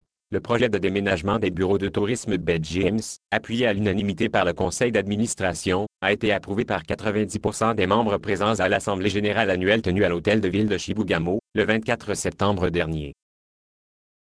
Texte de d�monstration
Monochrome Web vous propose d'�couter, via le lien ci-dessous, la d�monstration audio de : F�lix (Nuance RealSpeak; distribu� sur le site de Nextup Technology; homme; fran�ais canadien)...